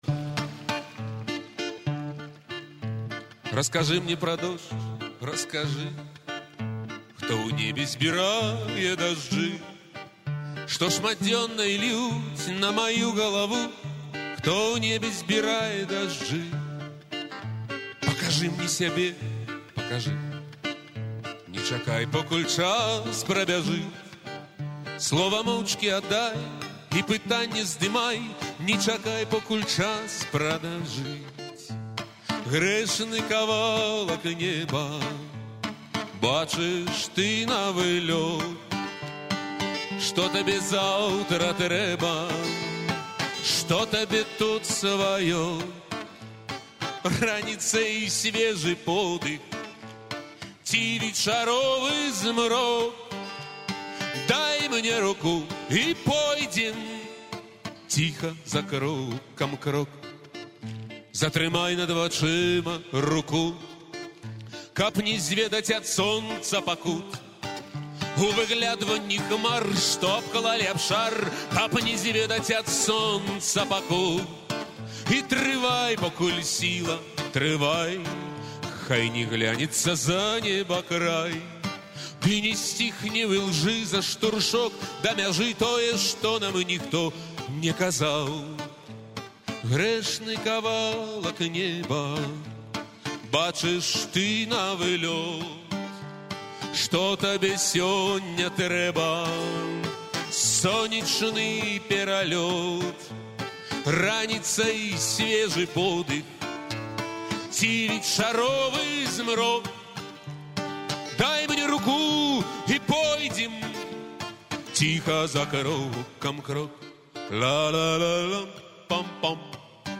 Исполняет автор.